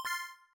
Add some more sound effects
thief-bribe.ogg